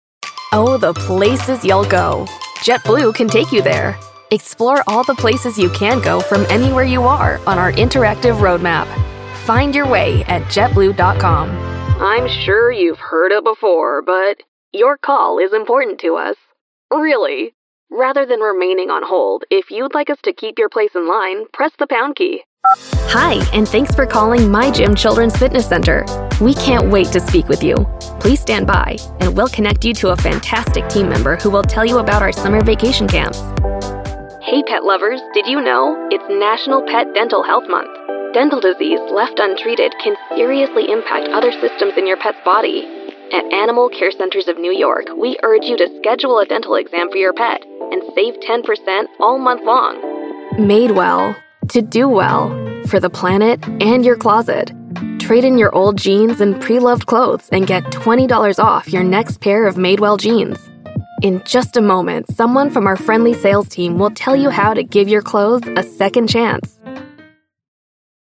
Comercial, Natural, Travieso, Versátil, Amable
Telefonía